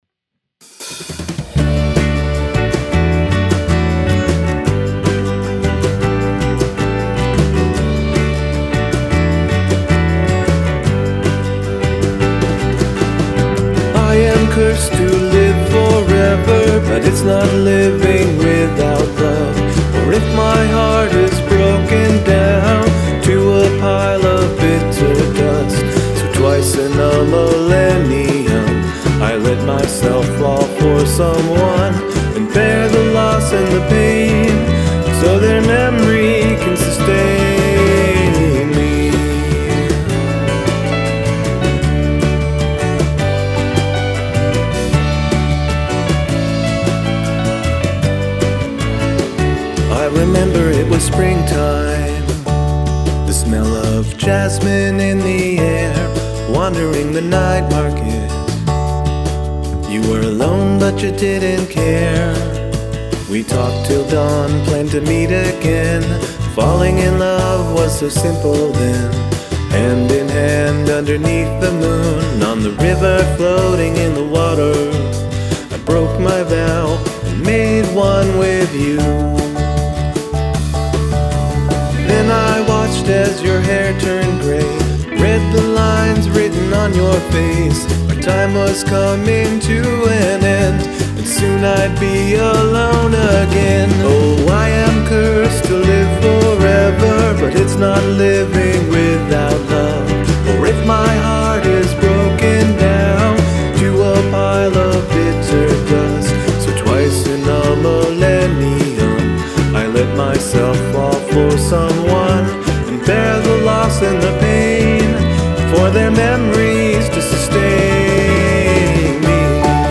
Sweet Jimmy Buffet vampire rock!